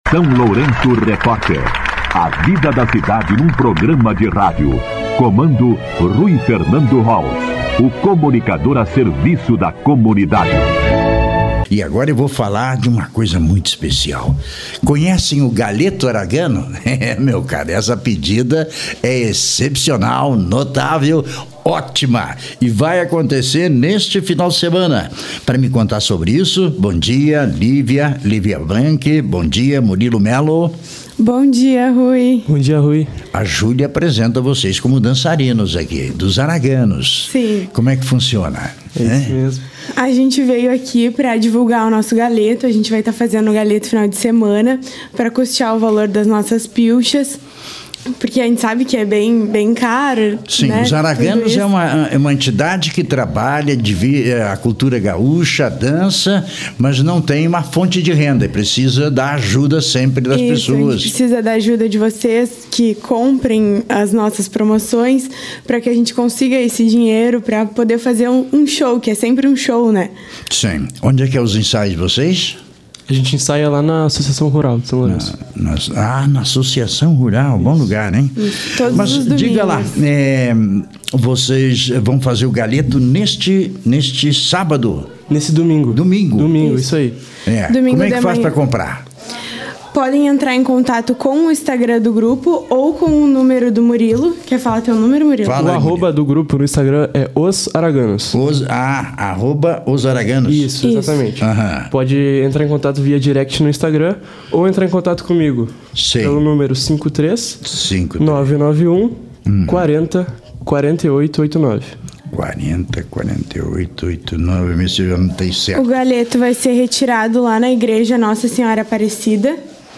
Entrevista com os dançarinos